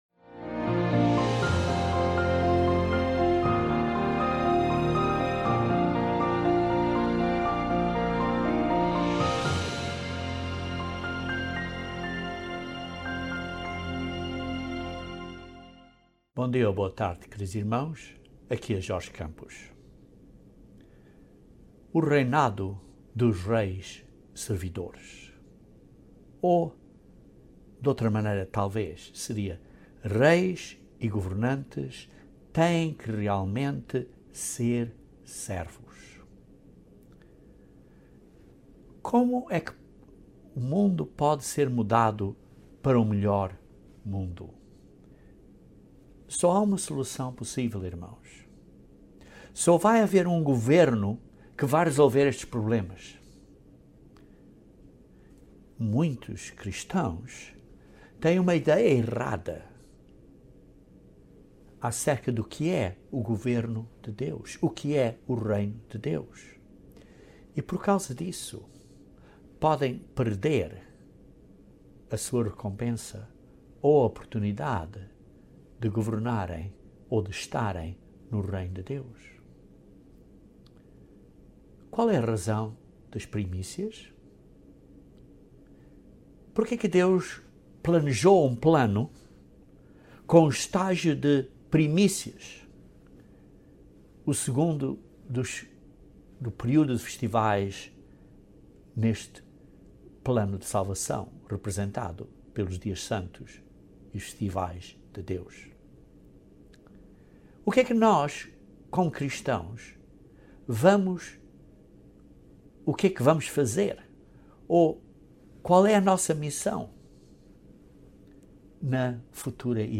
O Reino de Deus durante o milénio será muito diferente deste mundo. Uma grande diferença será como os governantes governarão. Este sermão aborda este tema e a importância de nos prepararmos agora para este reinado.